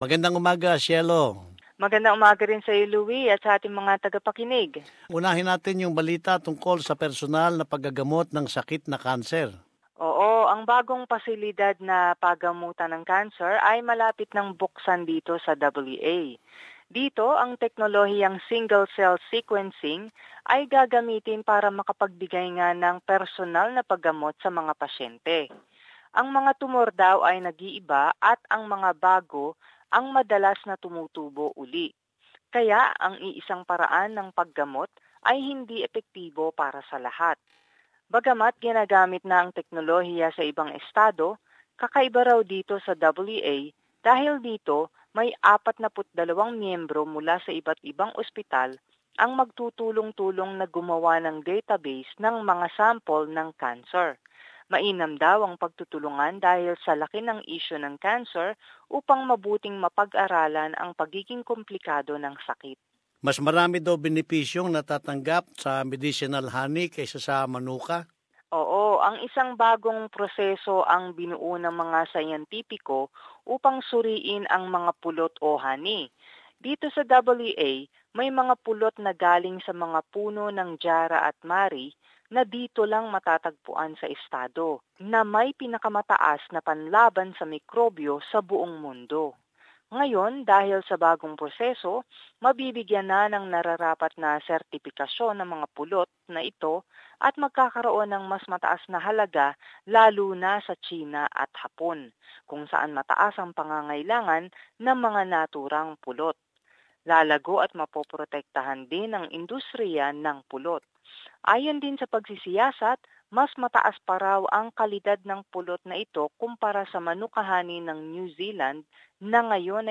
News from Western Australia